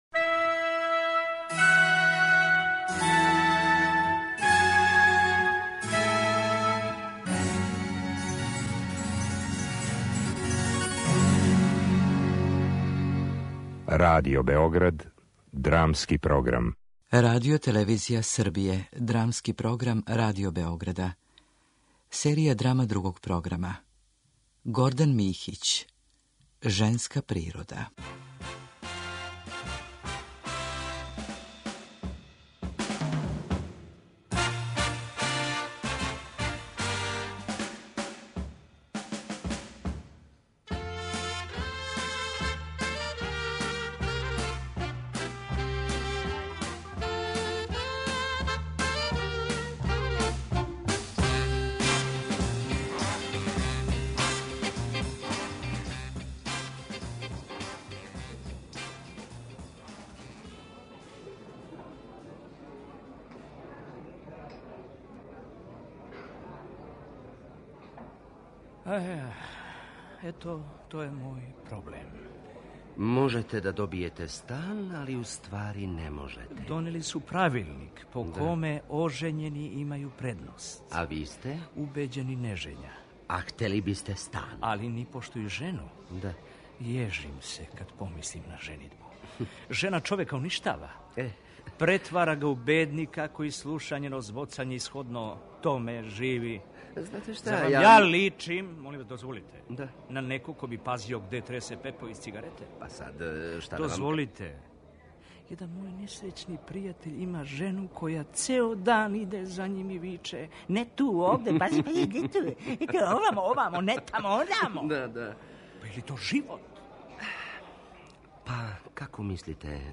Drama
U letnjem ciklusu posvećenom scenaristi i dramaturgu Gordanu Mihiću slušate radio-adaptaciju istoimenog TV scenarija iz autorovog humorističkog ciklusa „Neobične priče".